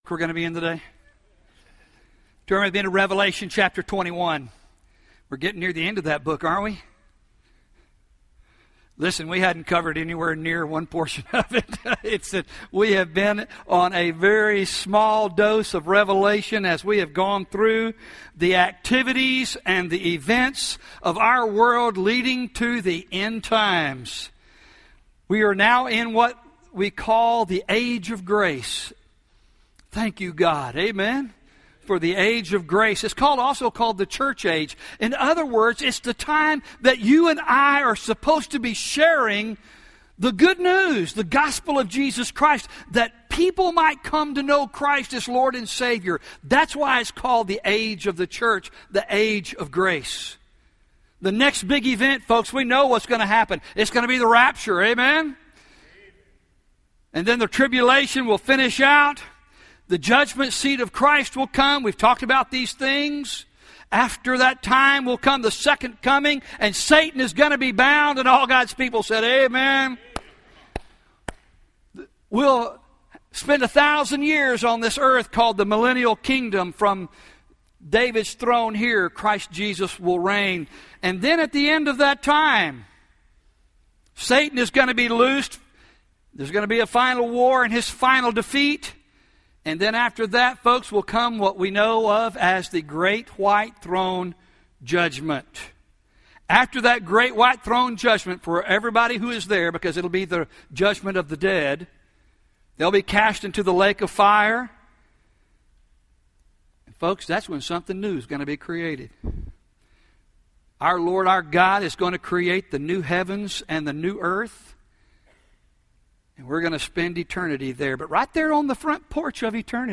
Sermons «